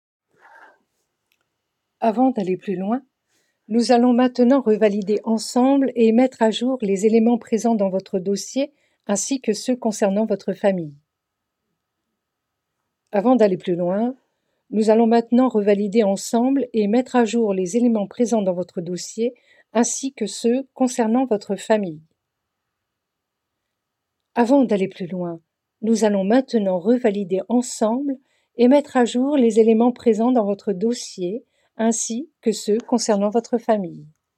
3 Essais voix conseillère bancaire
Voix off
- Mezzo-soprano